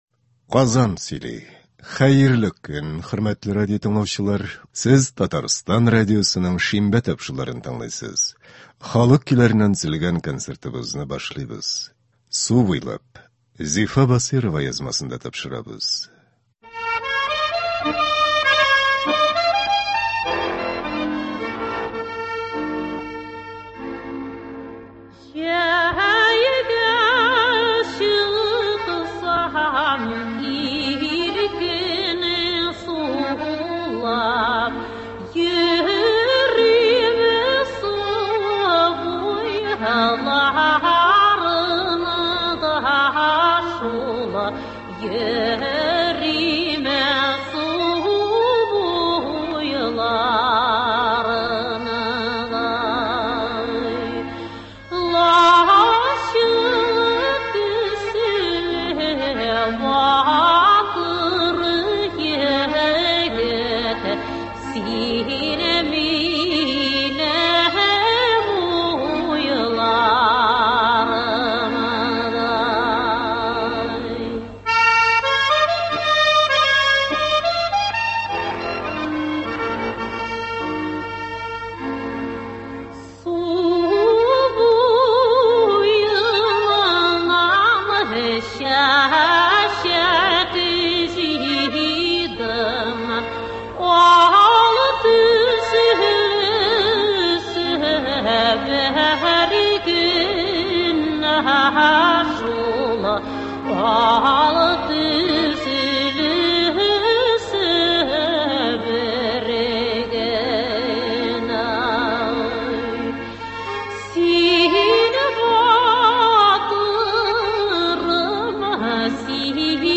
Татар халык көйләре (02.07.22)